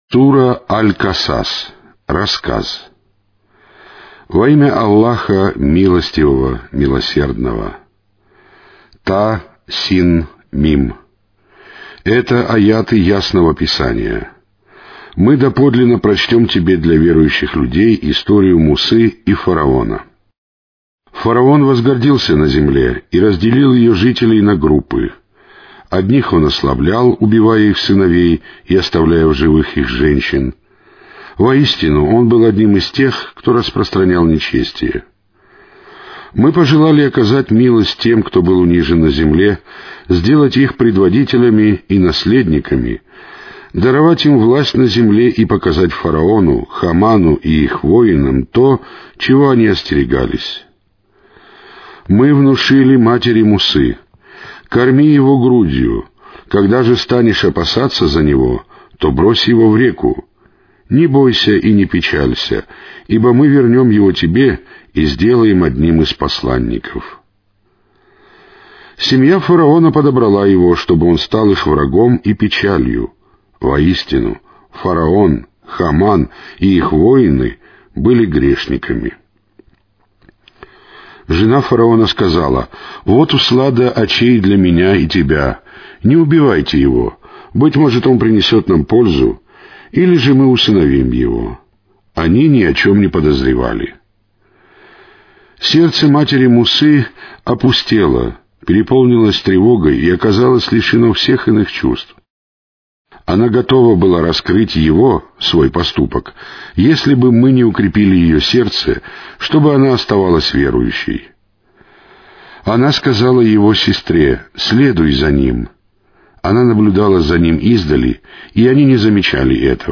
Каналы 1 (Mono).